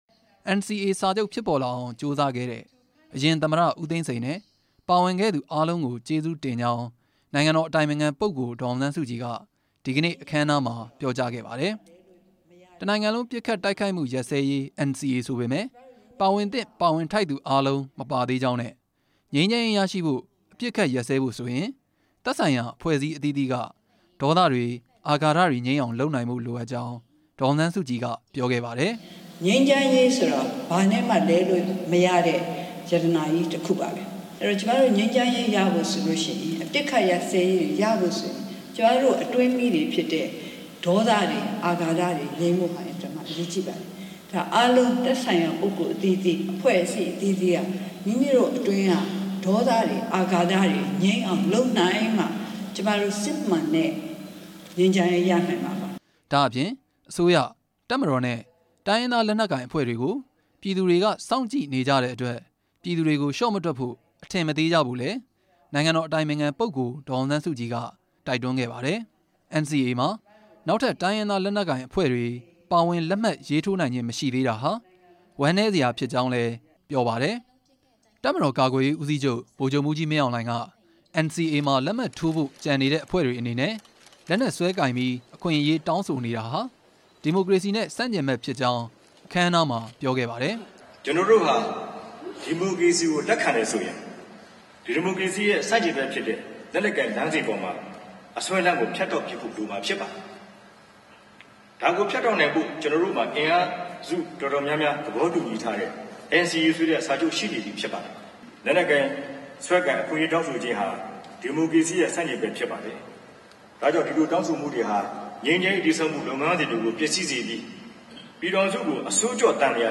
တစ်နိုင်ငံလုံး ပစ်ခတ်တိုက်ခိုက်မှု ရပ်စဲရေးစာချုပ် NCA လက်မှတ်ထိုးခြင်း တစ်နှစ်ပြည့် အထိမ်းအမှတ် အခမ်းအနားကို ဒီနေ့ နေပြည်တော်မှာ ကျင်းပခဲ့ပါတယ်။
အခမ်းအနားမှာ အစိုးရ၊ တပ်မတော်နဲ့ တိုင်းရင်းသားလက်နက်ကိုင် အဖွဲ့ခေါင်းဆောင်တွေ ပြောကြားခဲ့တဲ့ မိန့်ခွန်းတွေထဲက